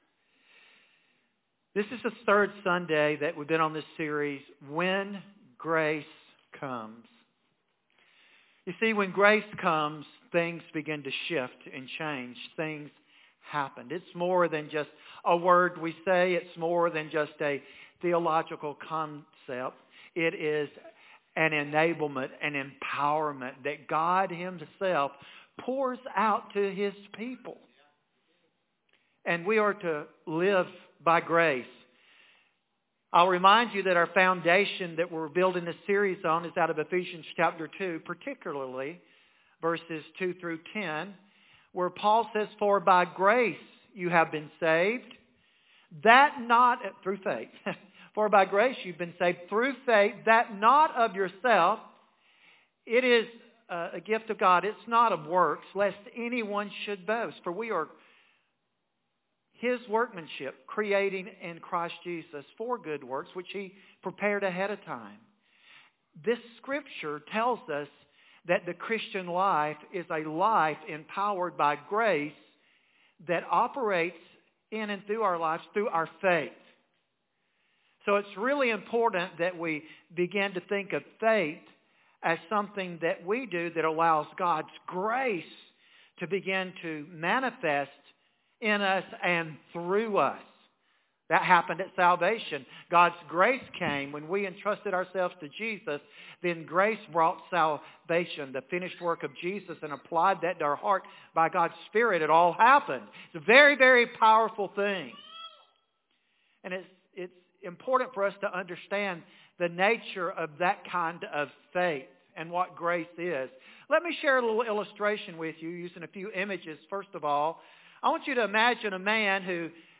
2025 Current Sermon When Grace Comes!